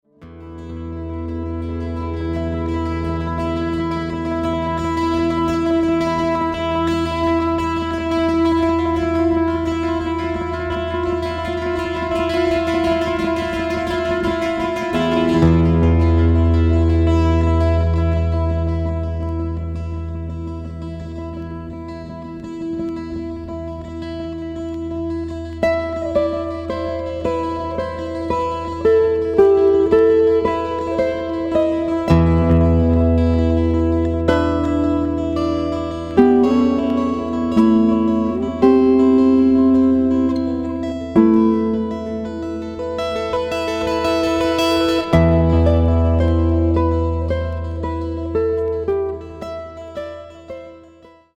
活動初期の82年にギターのデュオ編成で発表した作品。二本のギターの響きがしなやかに相交わる全5曲を収録。